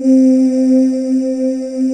Index of /90_sSampleCDs/USB Soundscan vol.28 - Choir Acoustic & Synth [AKAI] 1CD/Partition C/05-ANGEAILES